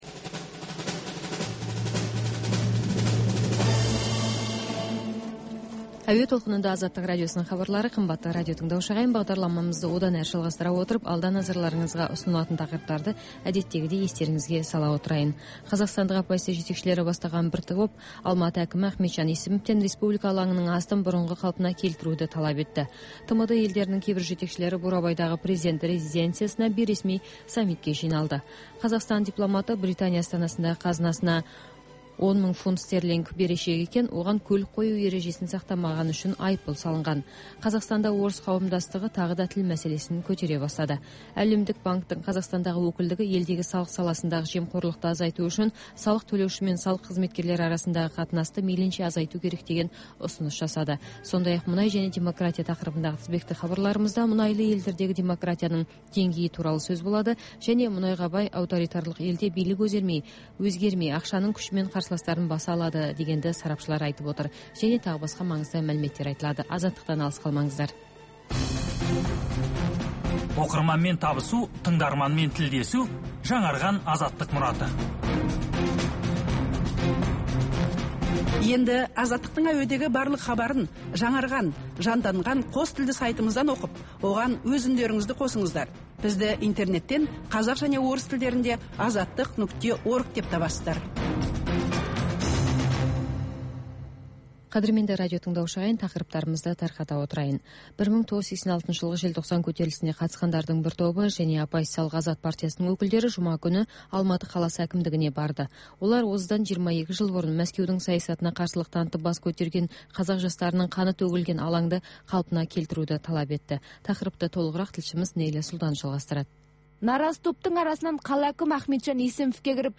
Бүгінгі күннің өзекті мәселесі, пікірталас, оқиға ортасынан алынған репортаж, қазақстандық және халықаралық сарапшылар пікірі, баспасөзге шолу.